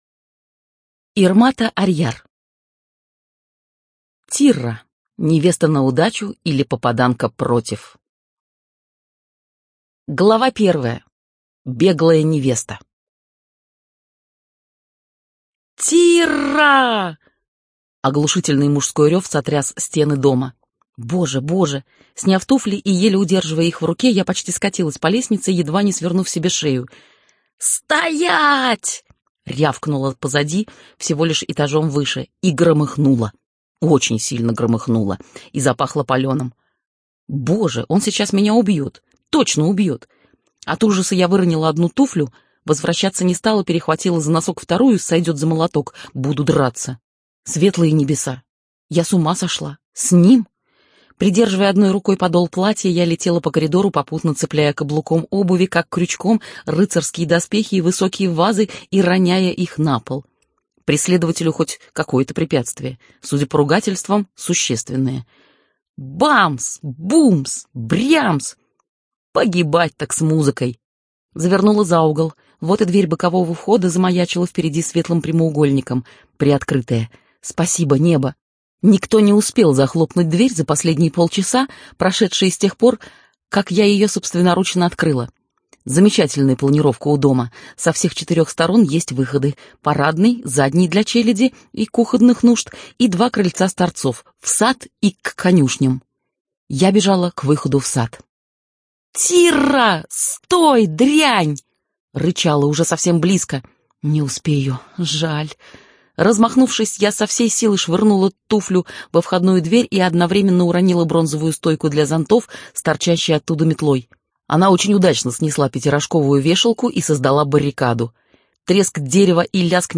Студия АРДИС предлагает вашему вниманию аудиокнигу «Тирра. Невеста на удачу, или Попаданка против!» российской писательницы Ирматы Арьяр.